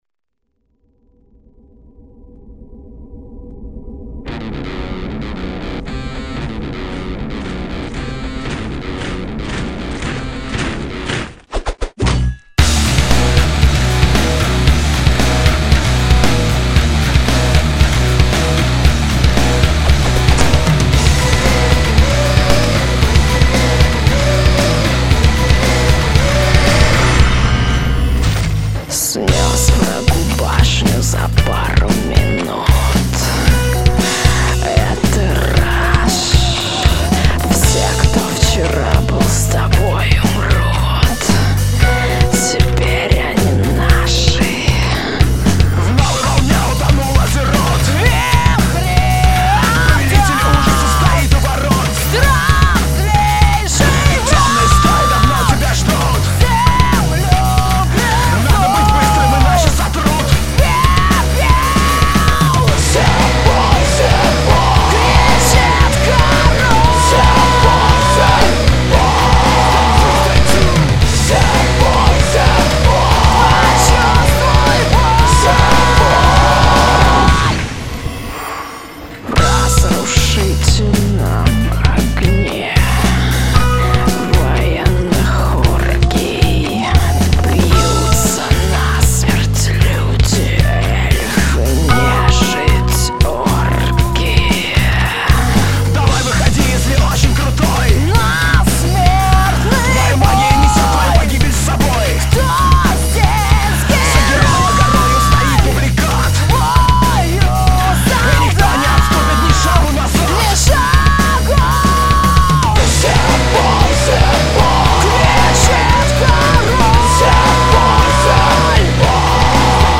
Жанр - Rock.